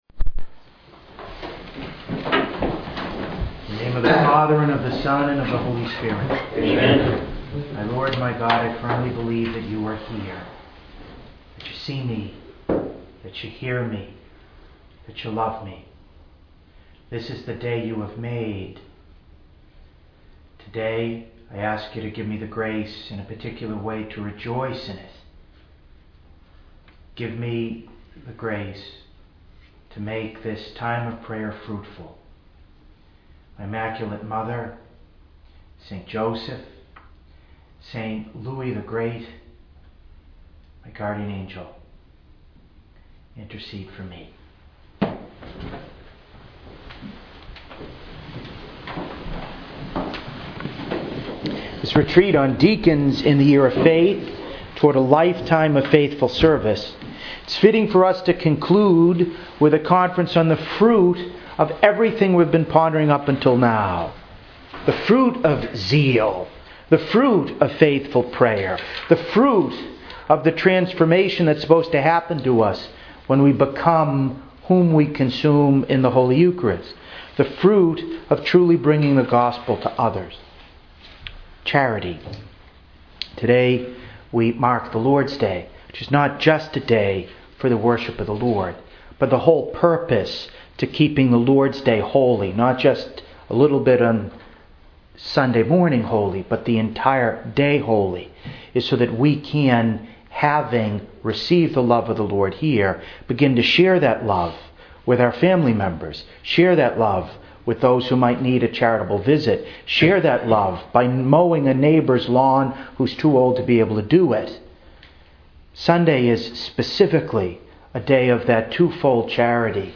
To listen to an audio of this conference, please click here: